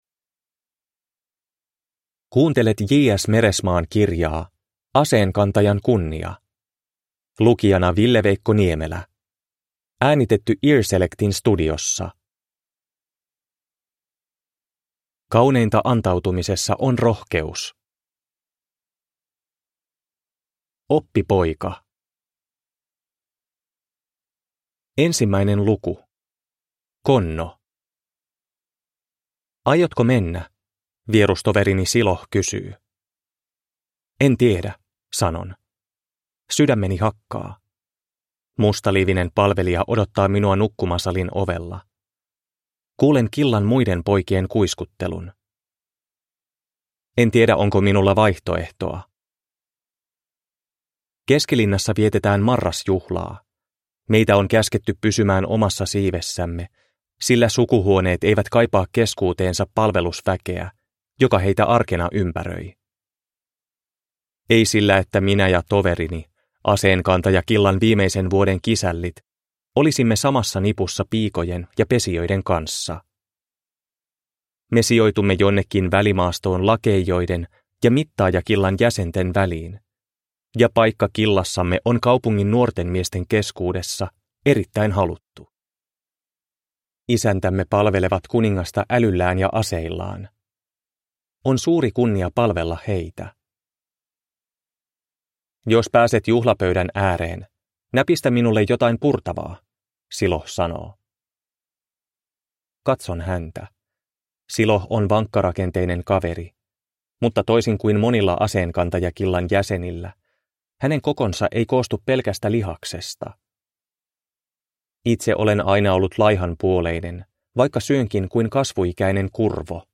Aseenkantajan kunnia (ljudbok) av J.S. Meresemaa